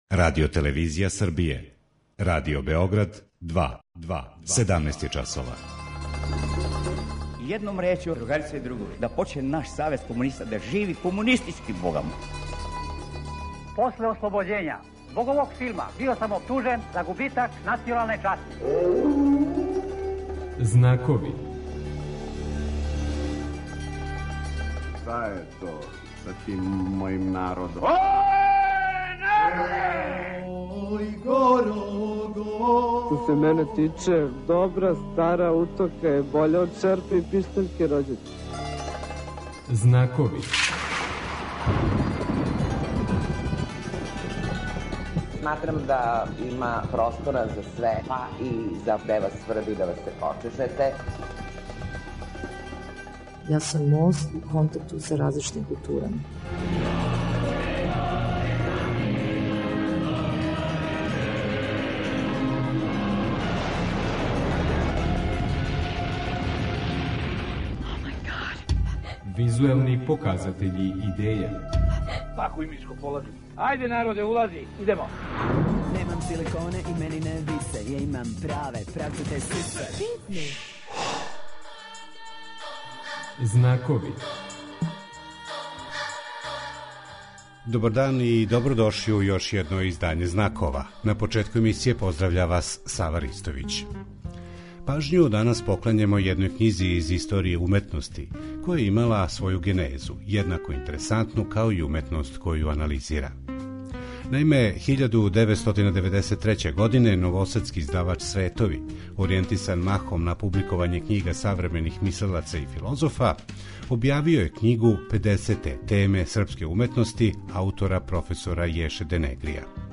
Гости Знакова ће бити: аутор проф др Јеша Денгри